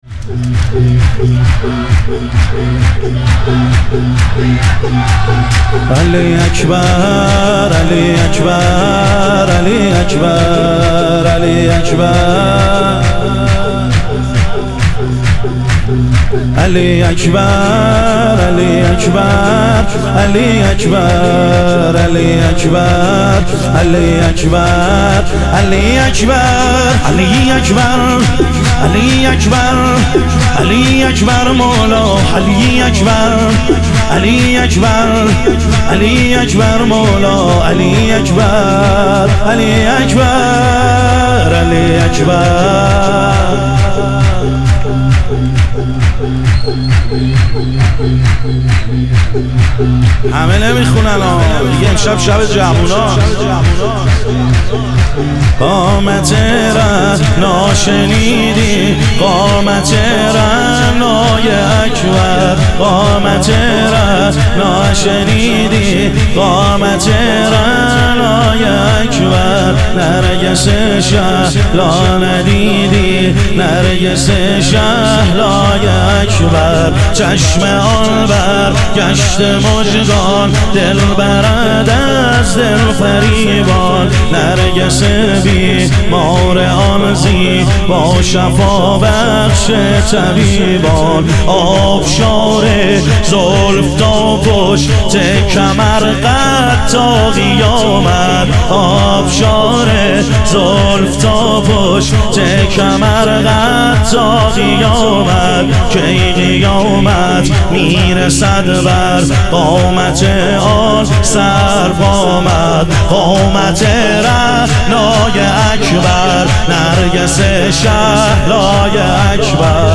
ظهور وجود مقدس حضرت علی اکبر علیه السلام - شور